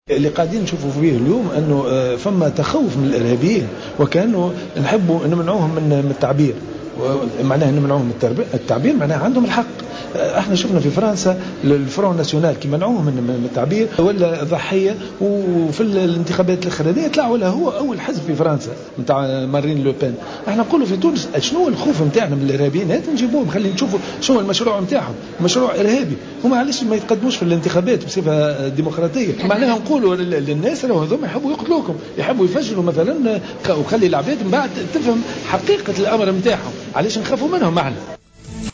خلال ندوة صحفية عقدتها النقابة للنظر في وضعيّة الإذاعات الخاصة و تعاملها مع موضوع الإرهاب